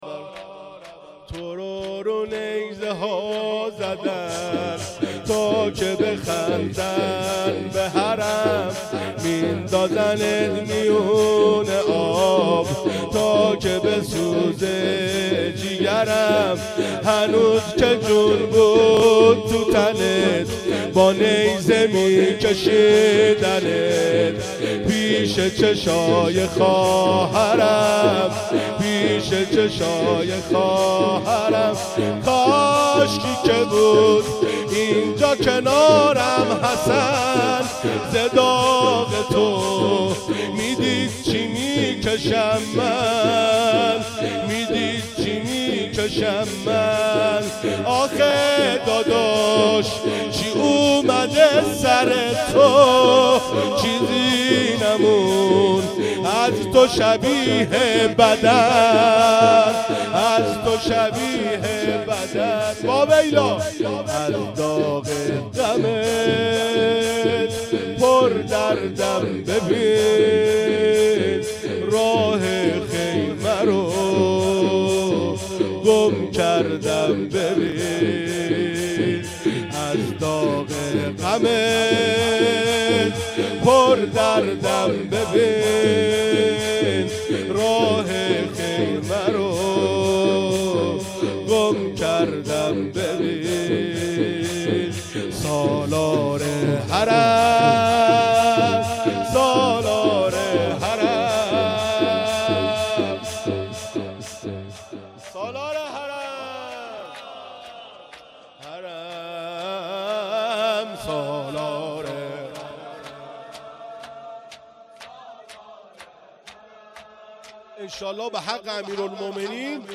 شب نهم محرم 98